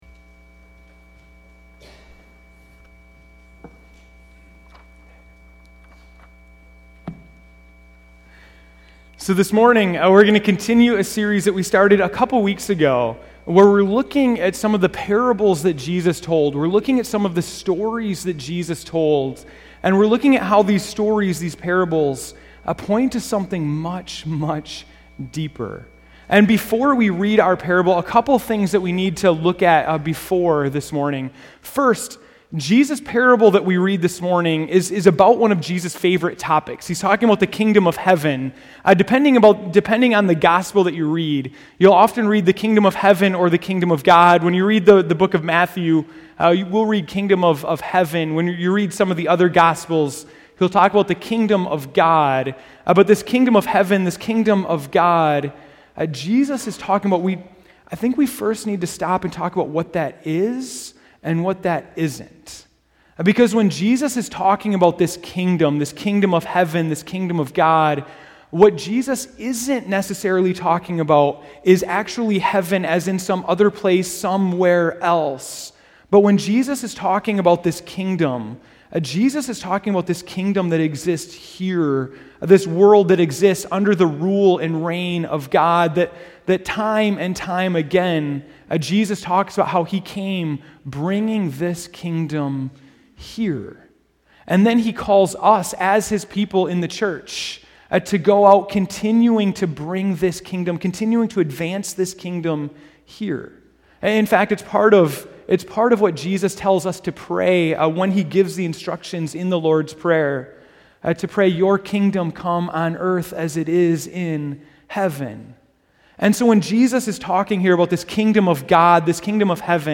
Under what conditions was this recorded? January 18, 2015 (Morning Worship)